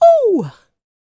D-YELL 1001.wav